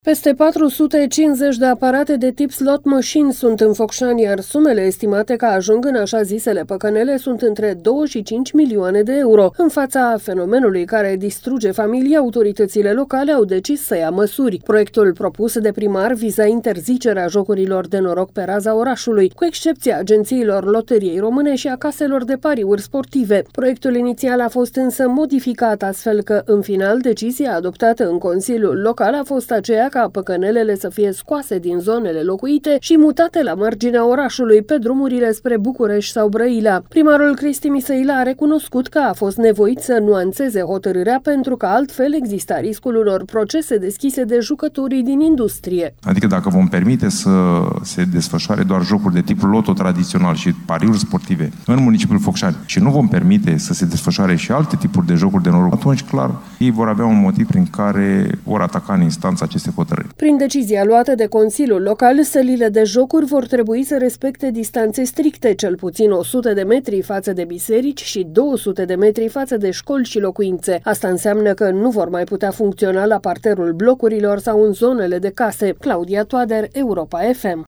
Primarul Cristi Misăilă a recunoscut că a fost nevoit să nuanțeze hotărârea, pentru că altfel exista riscul unor procese deschise de reprezentanții industriei: